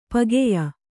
♪ pageya